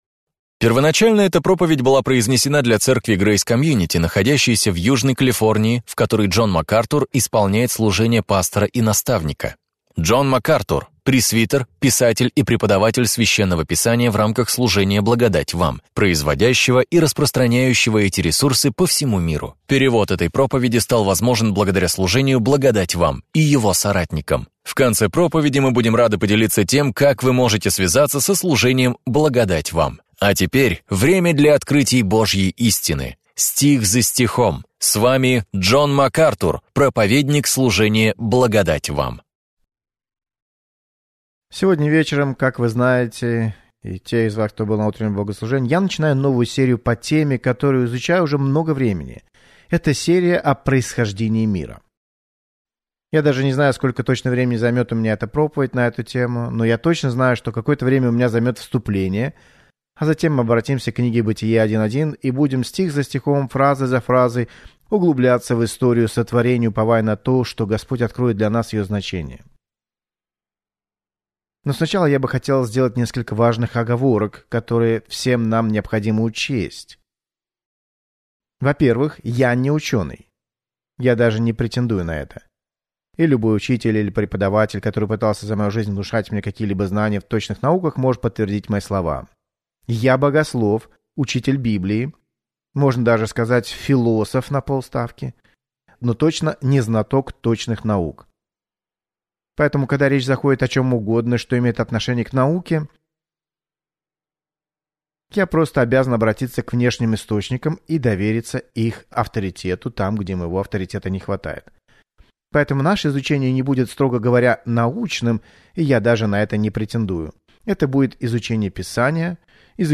В своей проповеди «Битва за начало» Джон Макартур раскрывает суть этих споров